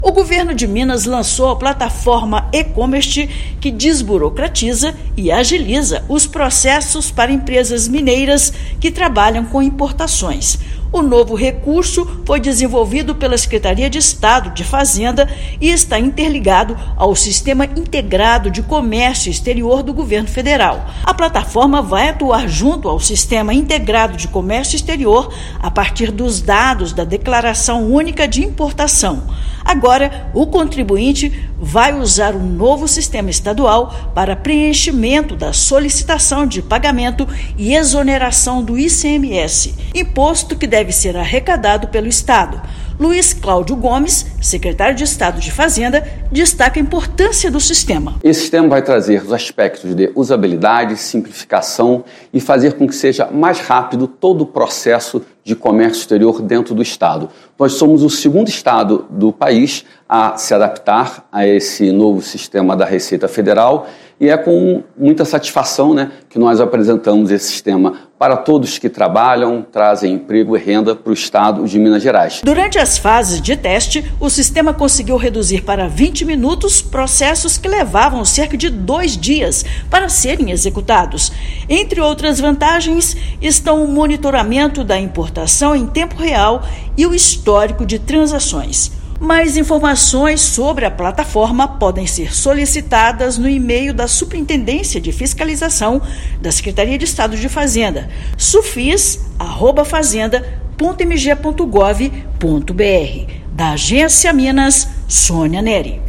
Na primeira fase de implementação, e-Comext impactará operações ocorridas pelo modal marítimo. Ouça matéria de rádio.